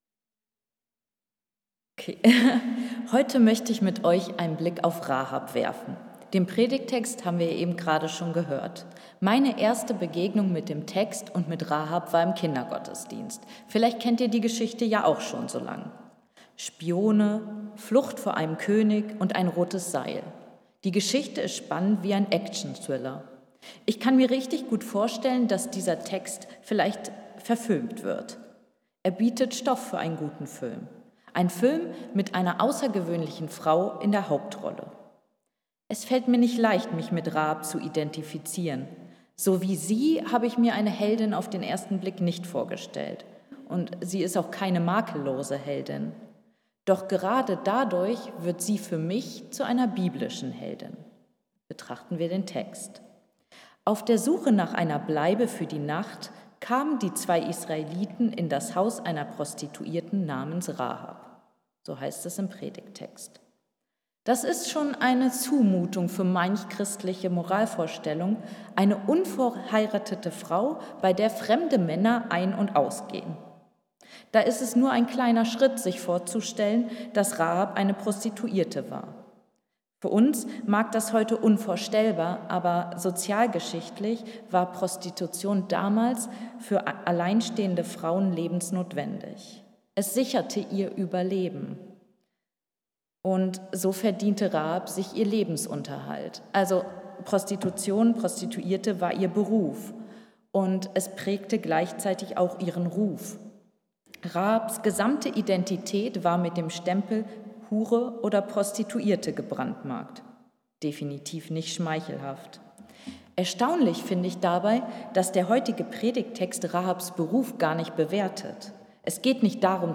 Predigten | Bethel-Gemeinde Berlin Friedrichshain